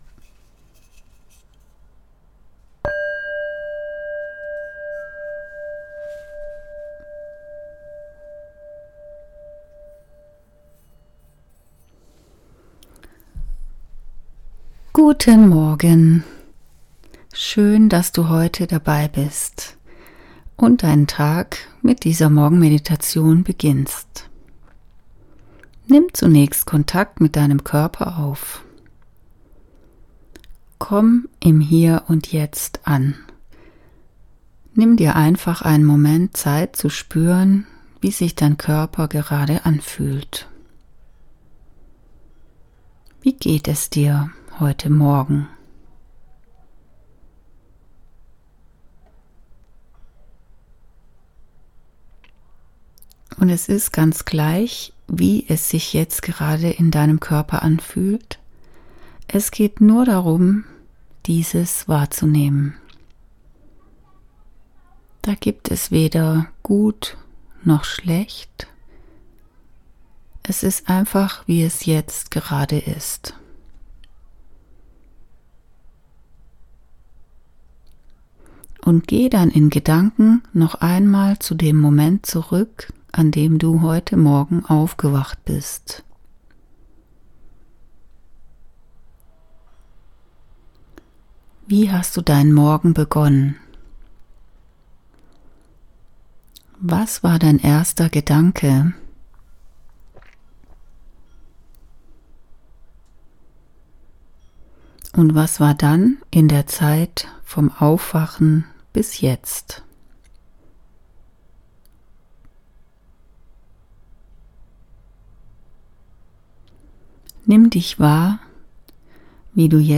Morgenmeditation.mp3